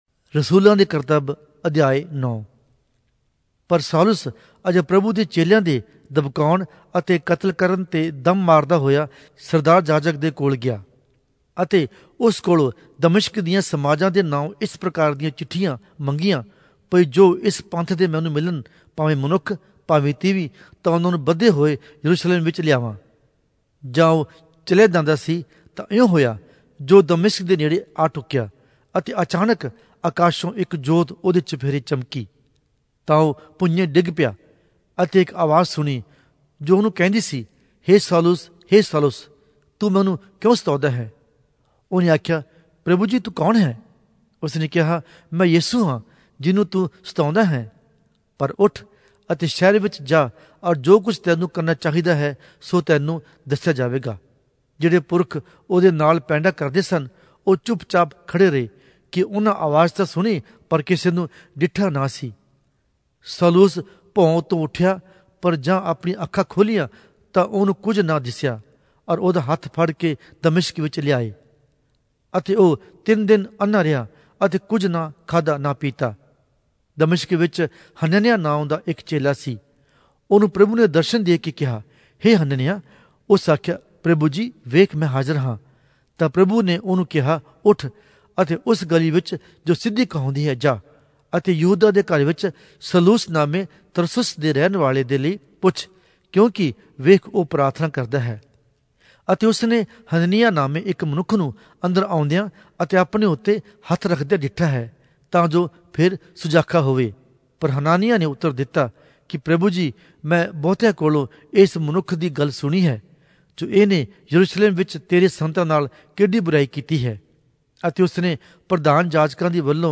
Punjabi Audio Bible - Acts 12 in Asv bible version